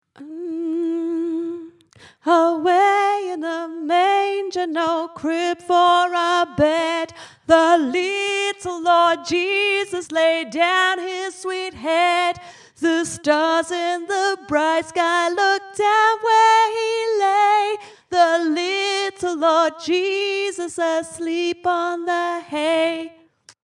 away-in-a-manger-alto
away-in-a-manger-alto.mp3